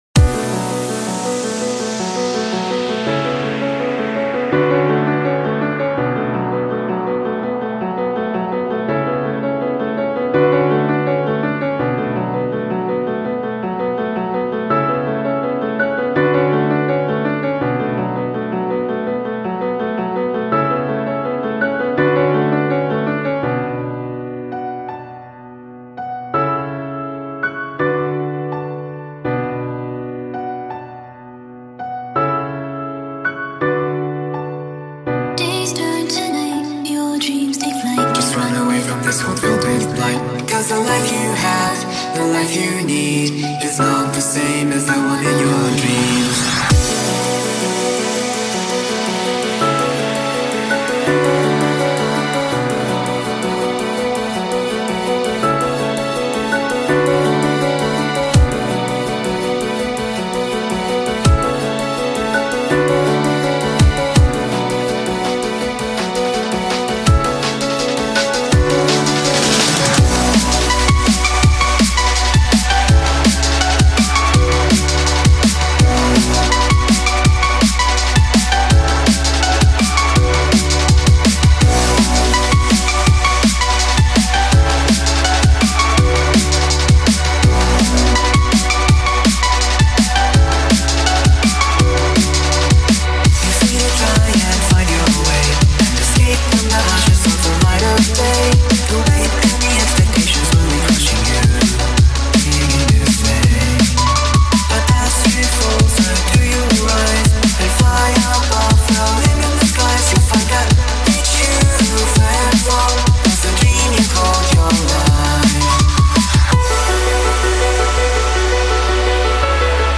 Enjoy some Drum and Bass.